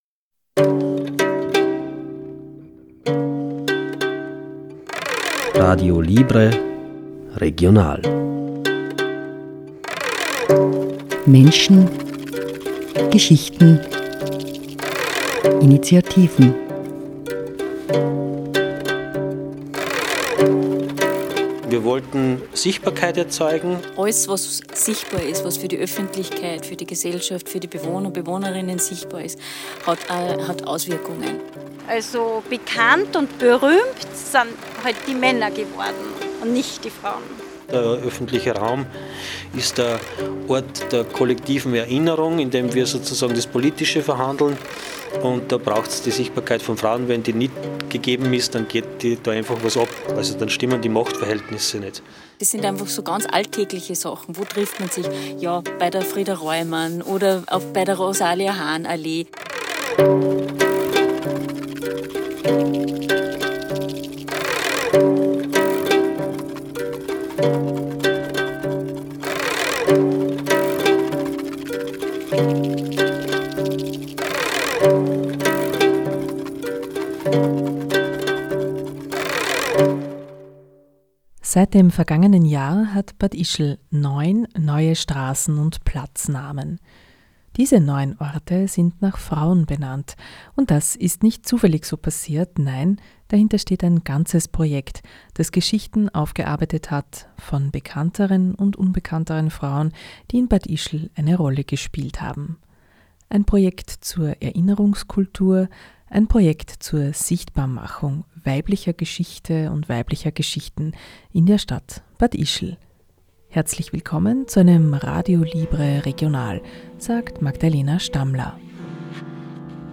Der Verein Freies Radio Salzkammergut betreibt seit 1999 ein zugangsoffenes Radio und versorgt die drei Bundesländer des Salzkammerguts auf acht Frequenzen mit einem werbefreien Hörfunk-Programm.